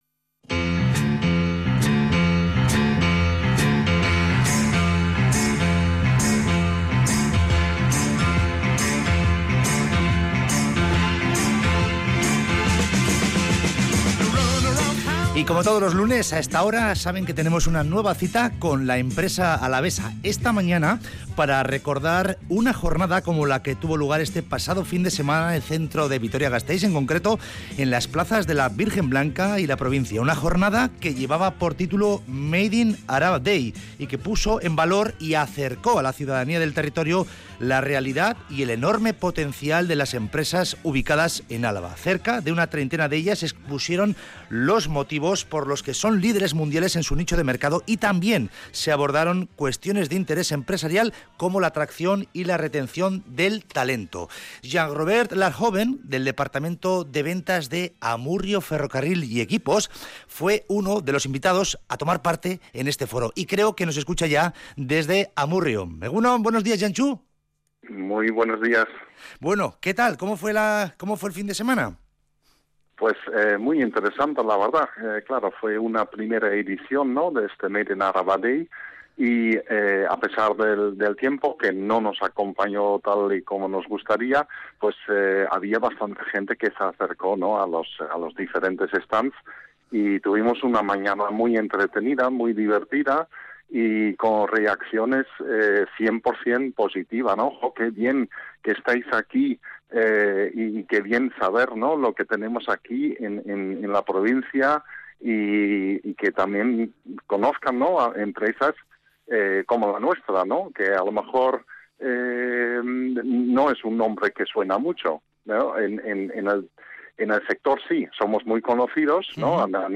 Entrevista en Radio Vitoria